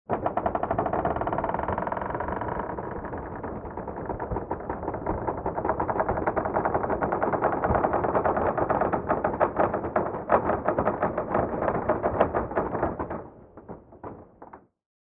Скрип избушки на курьих ножках при повороте передом